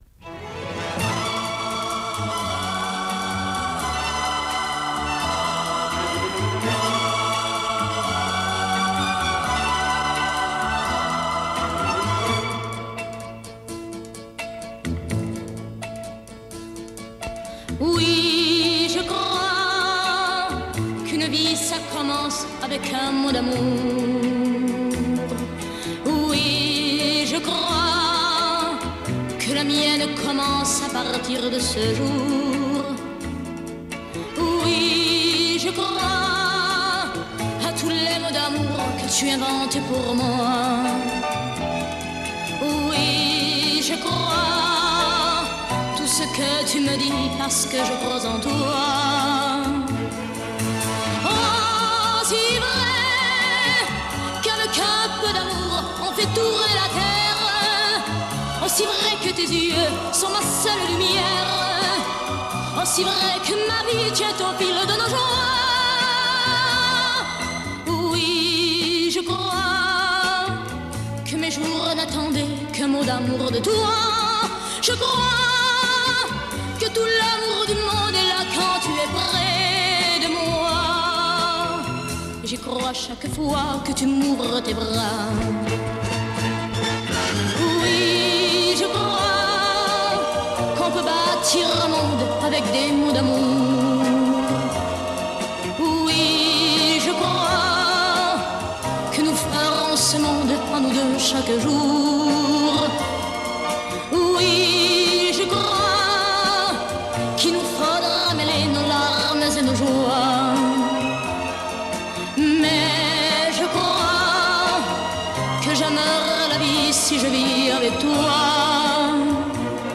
Chanson, Pop